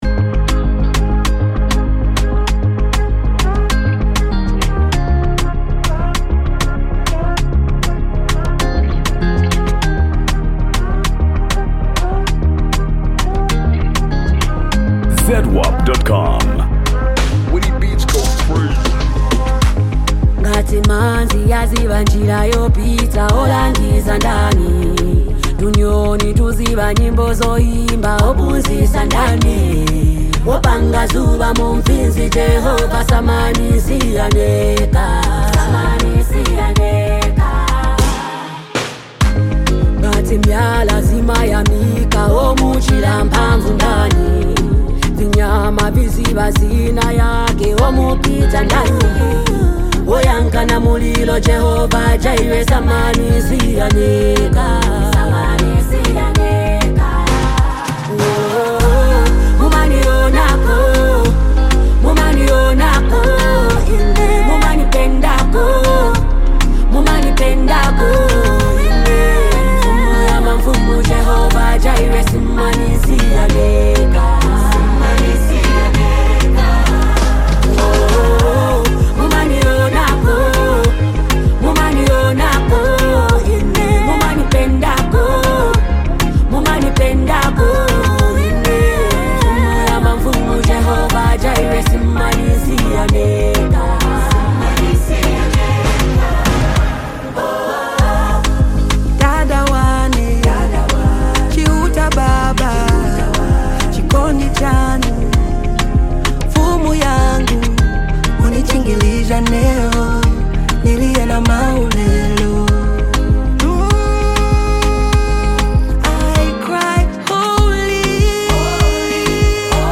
Género musical: Amapiano